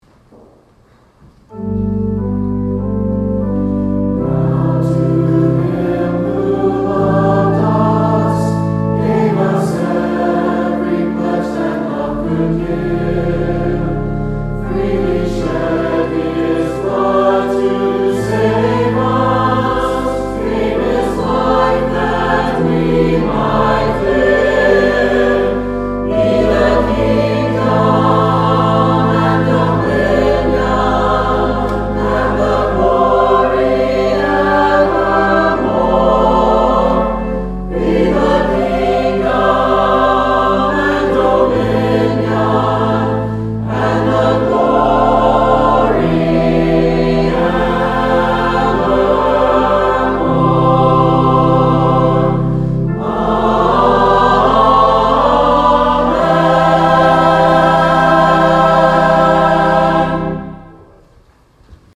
Call to Worship: Chancel Choir